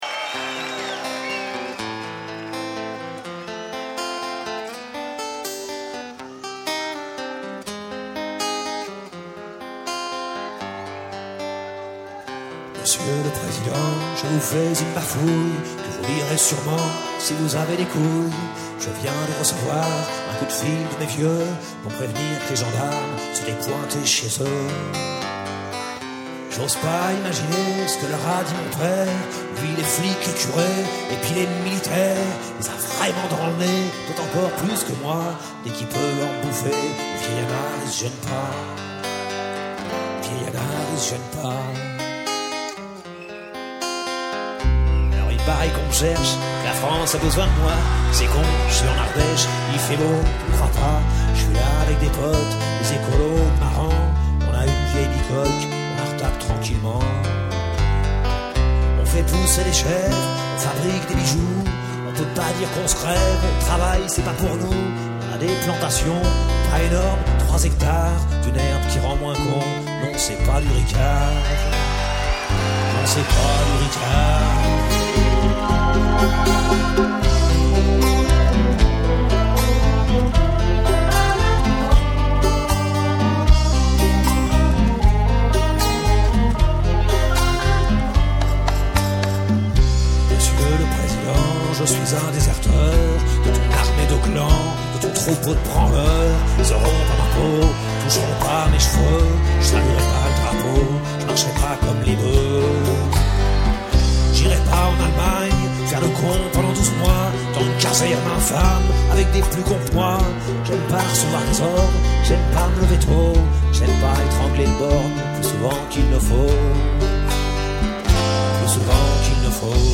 chanteur